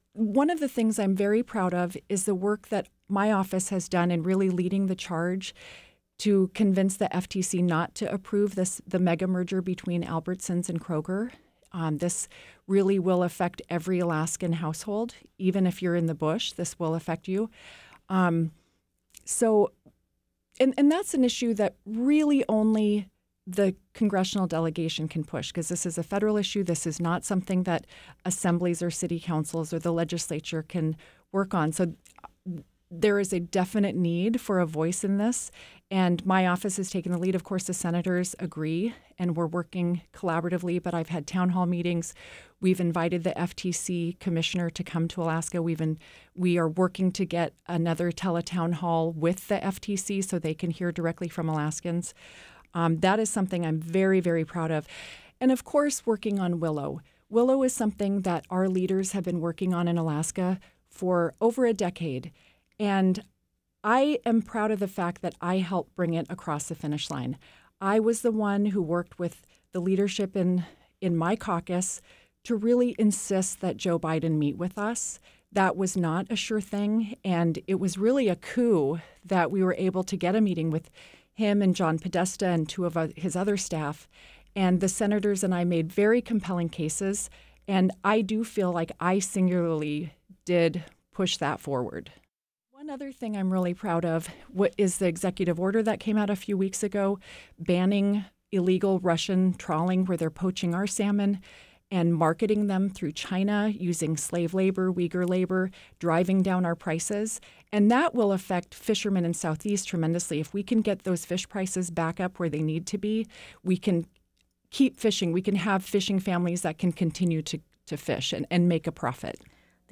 U.S. Rep. Mary Peltola in KTOO’s studio on Saturday, Jan. 27, 2024.
This interview has been edited for length and clarity.